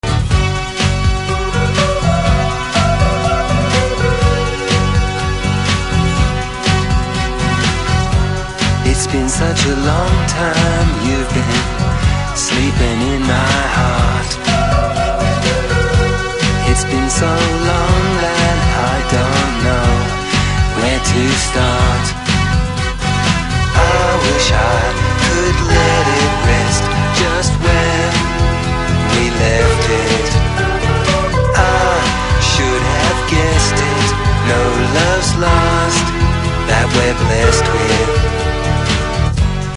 Recorded at La Maison (Bleu) and Hypnotech studios.